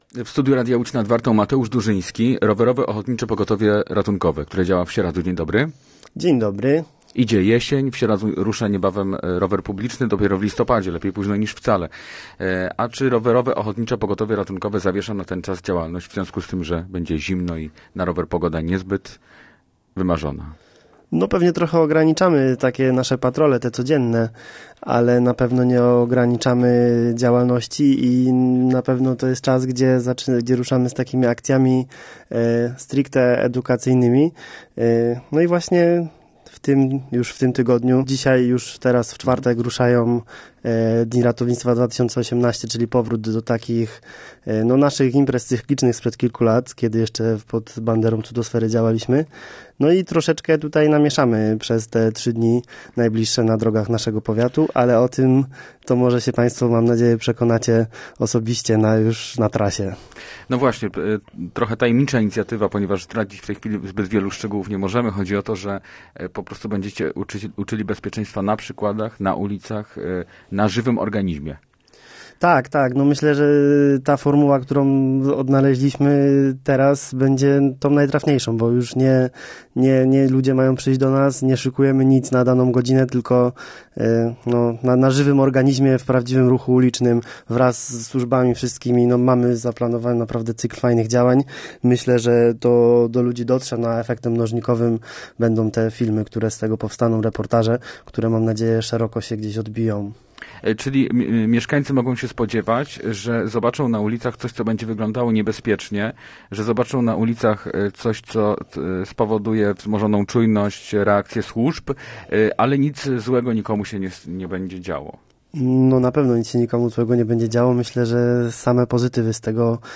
Posłuchaj całej rozmowy: Nazwa Plik Autor – brak tytułu – audio (m4a) audio (oga) Warto przeczytać Pogoda na piątek.